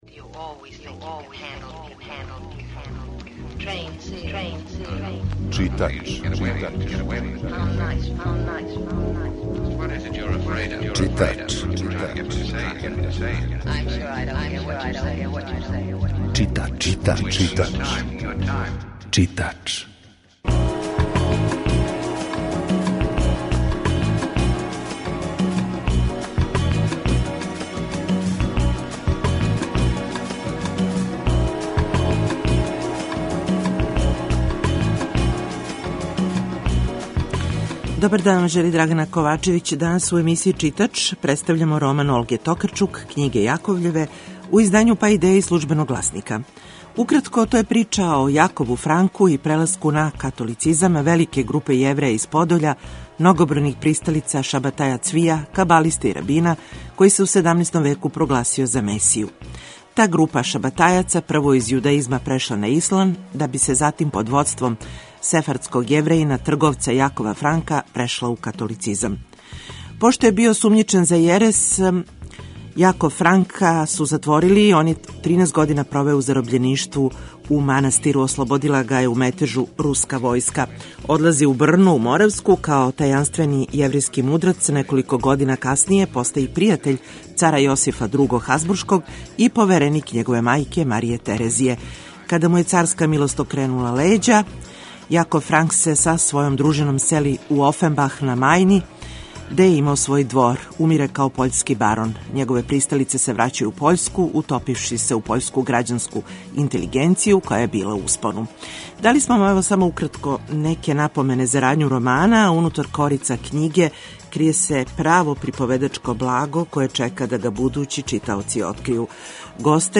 Чућемо и део разговора са Олгом Токарчук када је пре неколико година боравила у Новом Саду.
Емисија је колажног типа, али је њена основна концепција – прича о светској књижевности